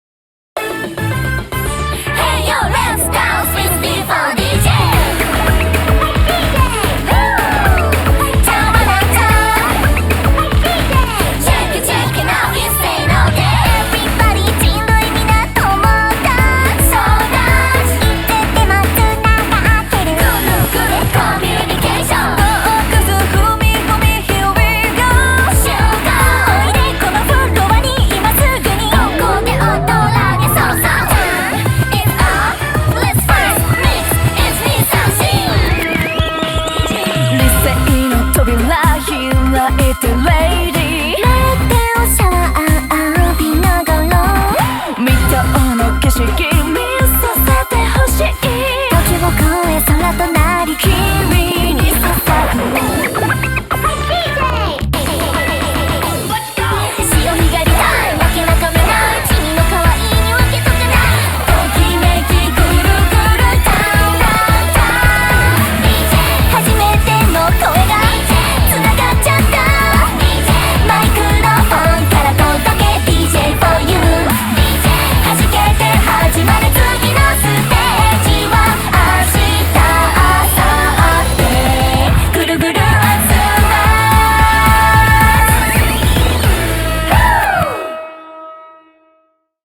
BPM125-220
Audio QualityMusic Cut